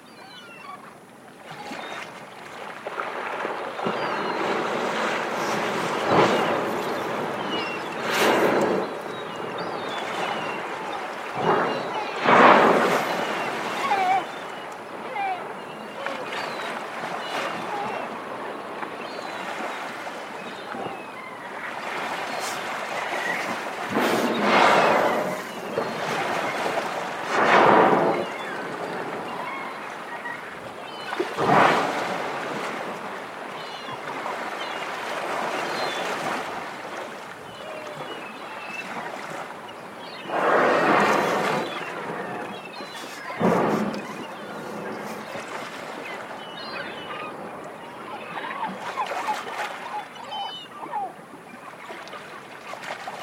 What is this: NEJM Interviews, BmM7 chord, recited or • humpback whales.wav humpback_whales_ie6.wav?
• humpback whales.wav humpback_whales_ie6.wav